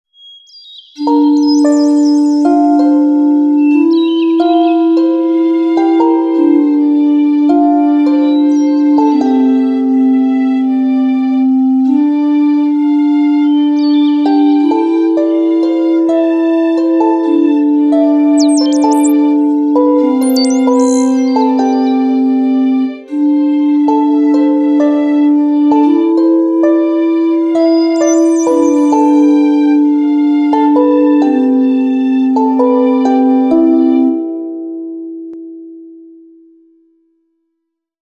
Some quick loopable music I made for the menu of my game.